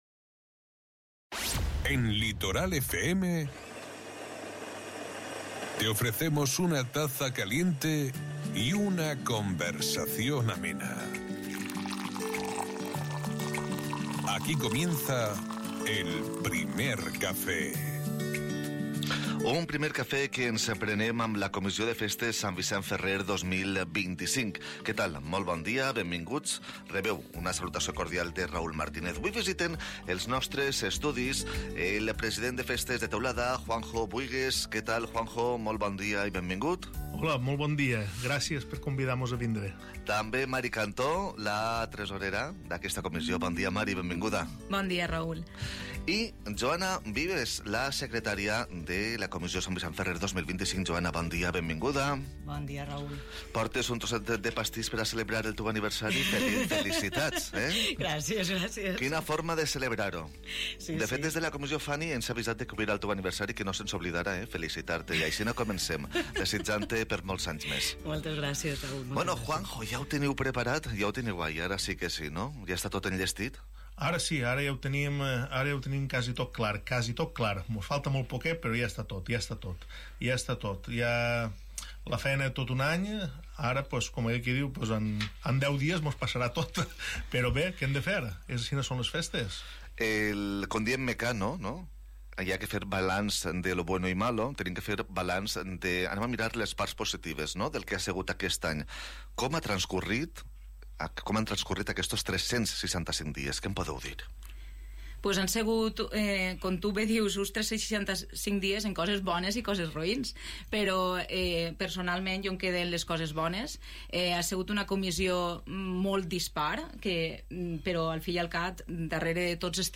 Durante la entrevista, han compartido detalles sobre el intenso trabajo que ha supuesto preparar esta edición, la ilusión que se respira en el municipio y la programación de unos días que prometen ser inolvidables.